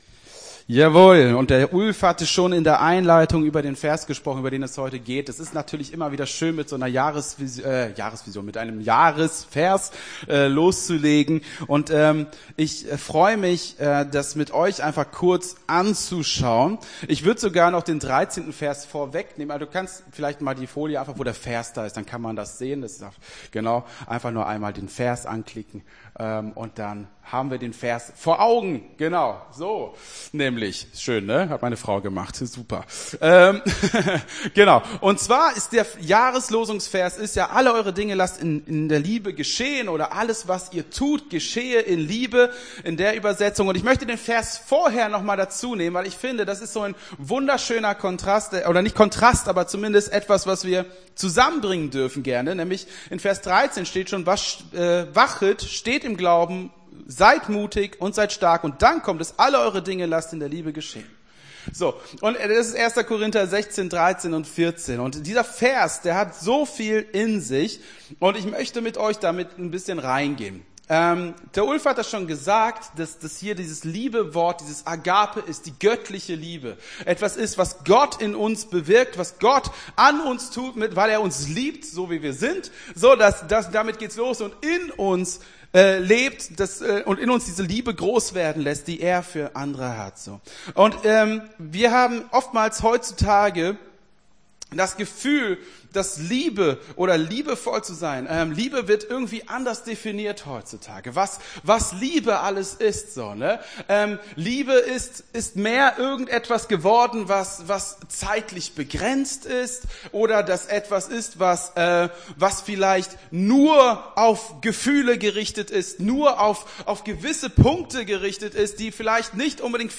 Gottesdienst 07.01.24 - FCG Hagen